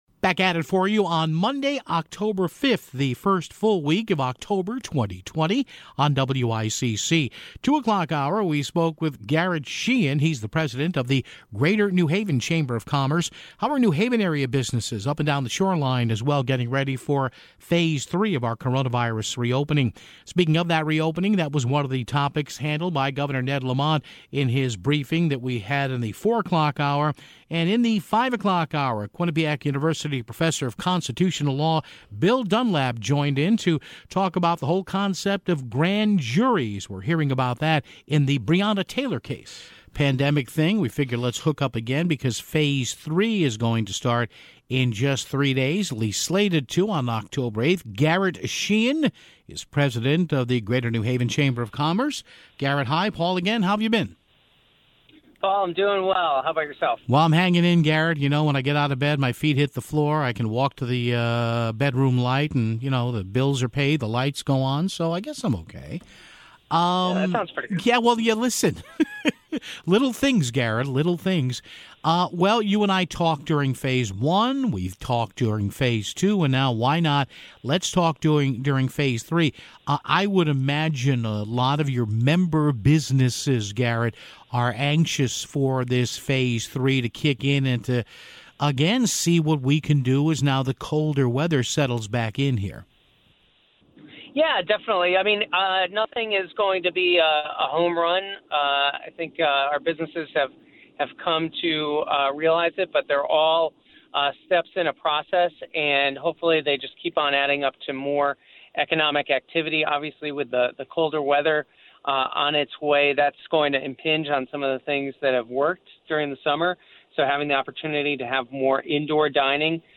Governor Ned Lamont in his meeting with the press would also face questions on phase three(11:32)